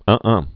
(ŭɴŭɴ, ŭɴŭɴ)